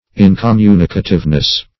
In`com*mu"ni*ca*tive*ness, n. --Lamb.